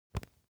ui_click.wav